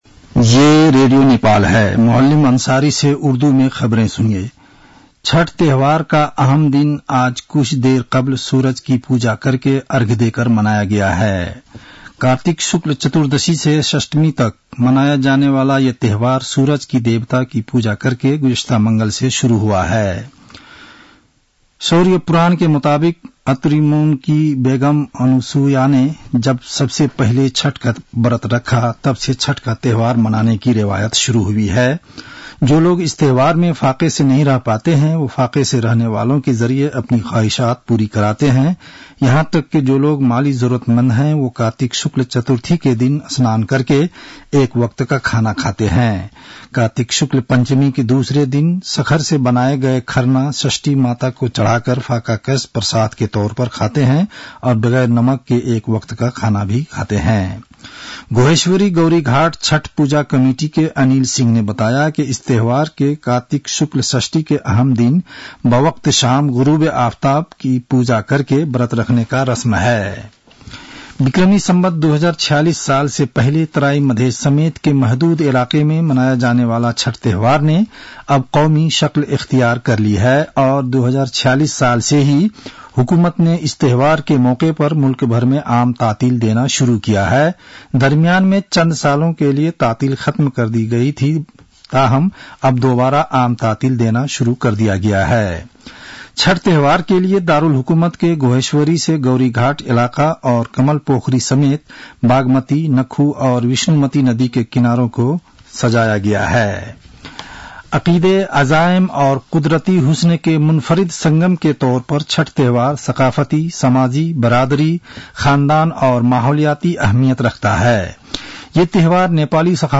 उर्दु भाषामा समाचार : २३ कार्तिक , २०८१